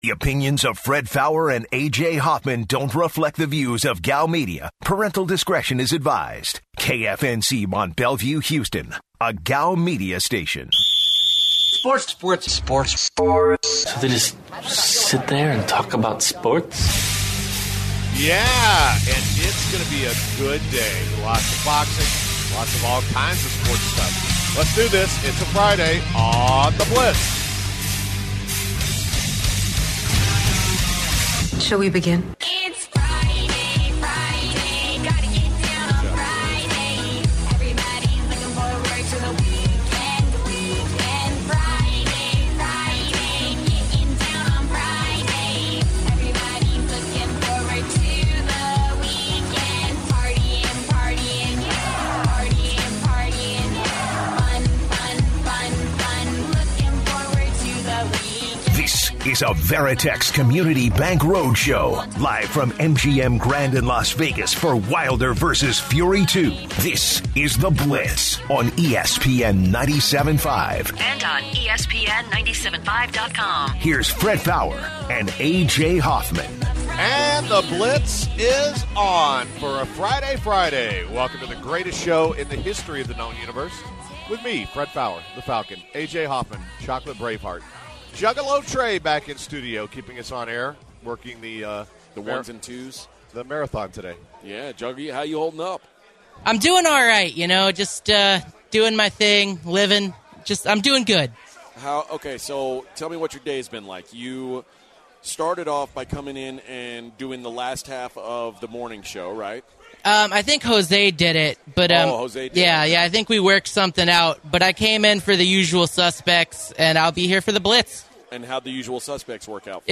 The guys are doing a remote show live from the MGM Grand in Las Vegas for Wilder vs Fury II! They start the show by sharing their plans for the weekend and the lively atmosphere as Vegas prepares for the fight.